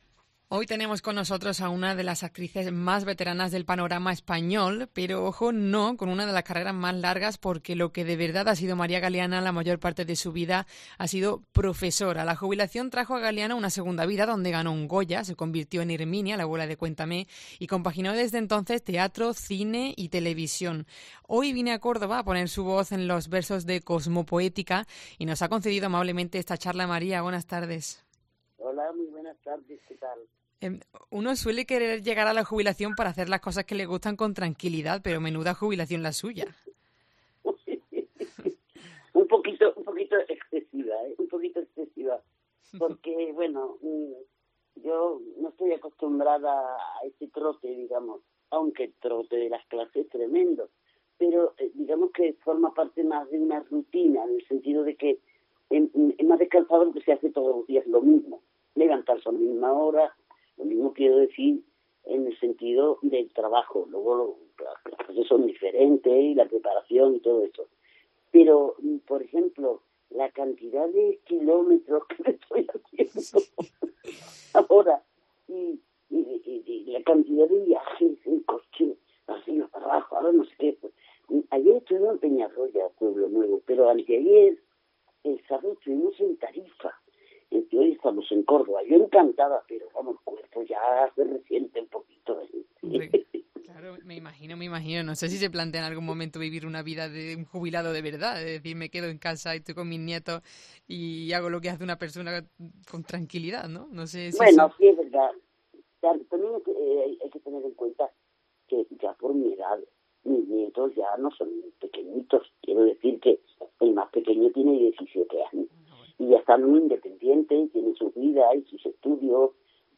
Escucha la entrevista a la actriz María Galiana